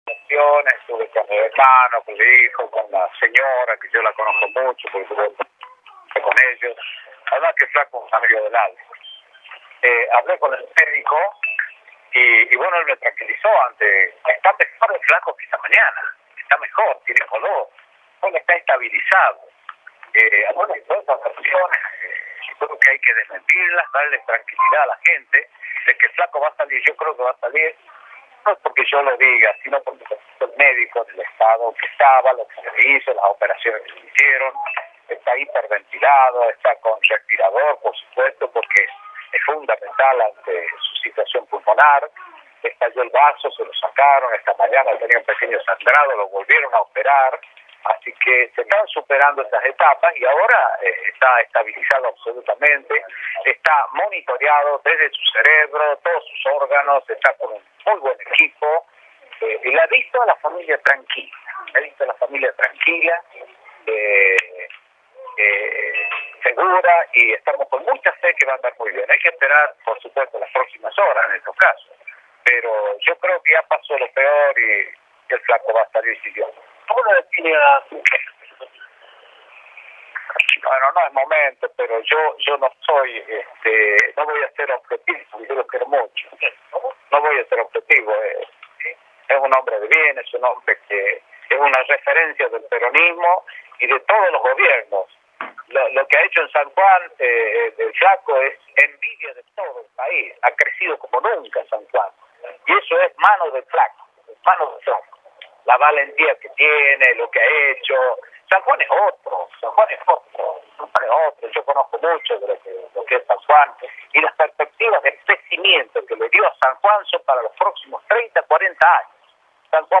El gobernador de La Rioja brindó declaraciones desde el Hospital Rawson de la provincia cuyana.